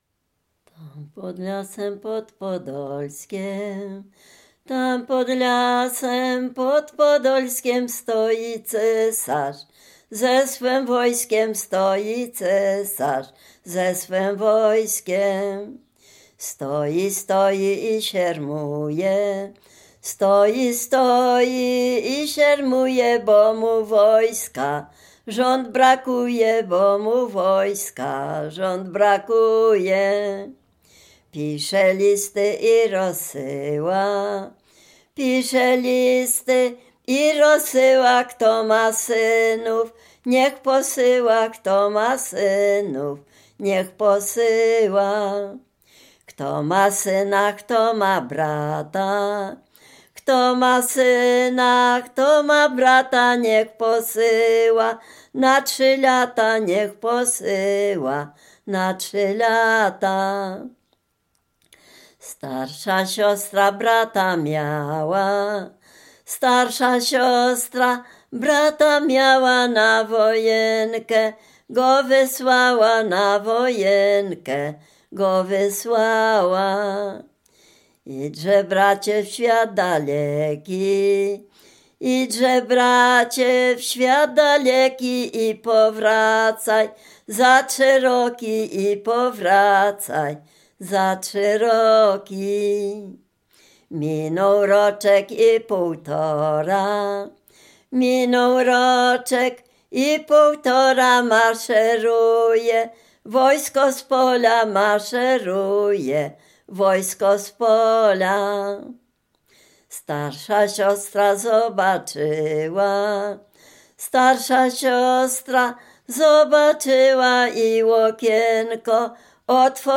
Dolny Śląsk, powiat bolesławiecki, gmina Nowogrodziec, wieś Zebrzydowa
Ballada
ballady wojenkowe